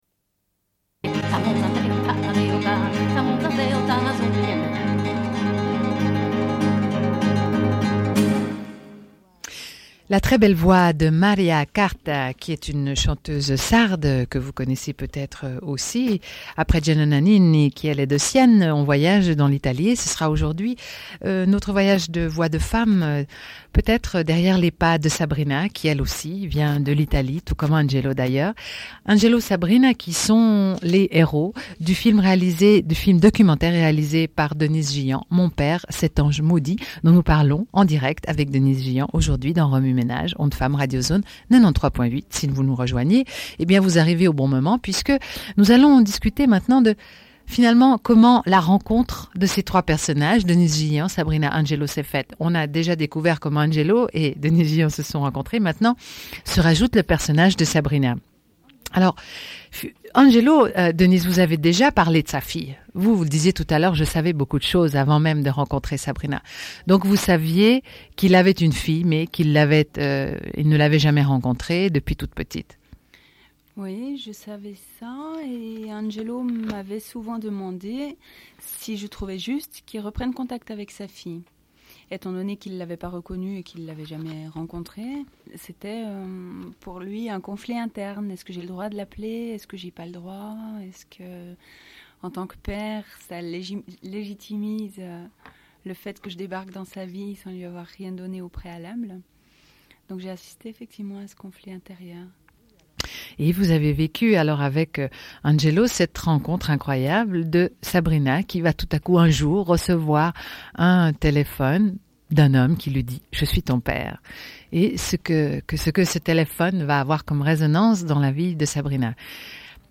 Une cassette audio, face B29:06